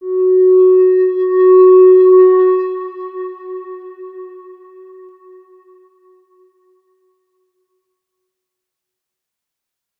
X_Windwistle-F#3-ff.wav